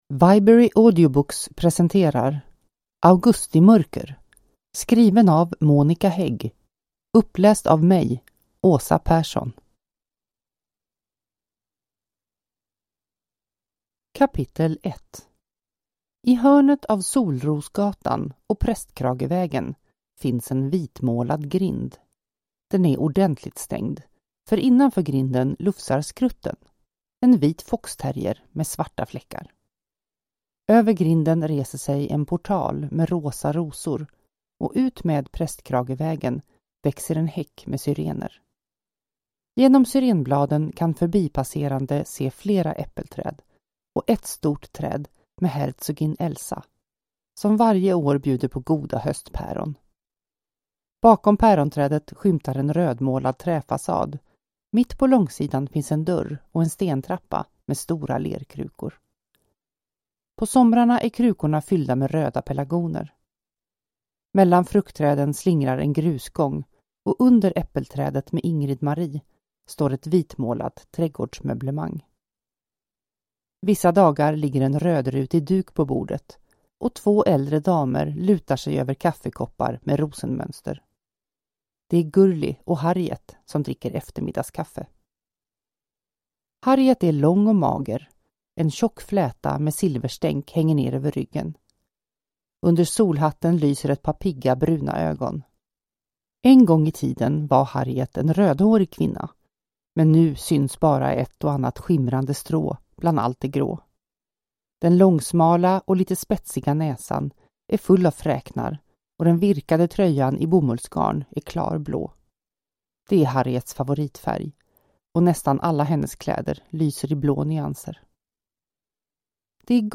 Augustimörker (ljudbok) av Monika Häägg